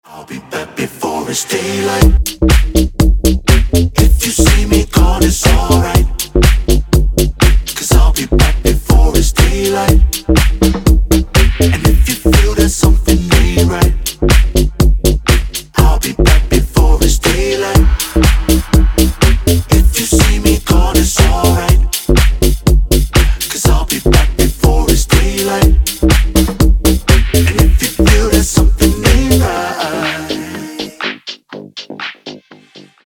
мужской вокал
deep house
Electronic
club